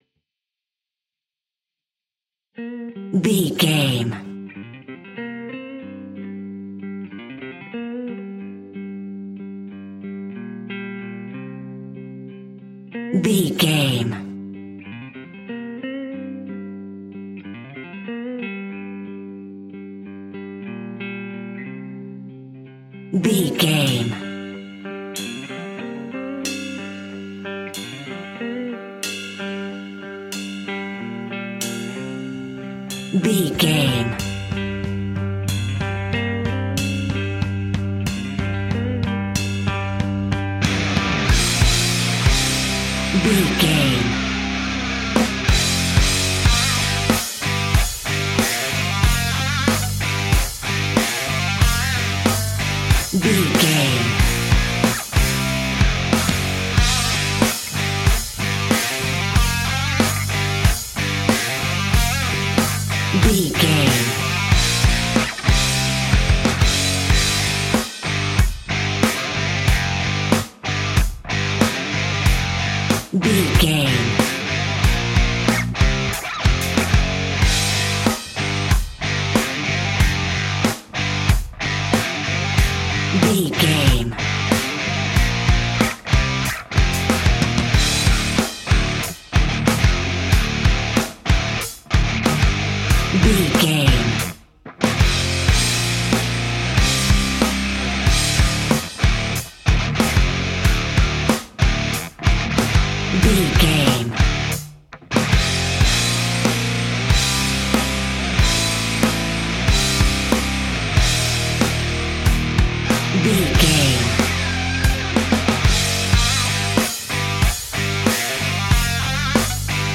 Ionian/Major
energetic
driving
heavy
aggressive
electric guitar
bass guitar
drums
hard rock
blues rock
distortion
rock instrumentals
distorted guitars
hammond organ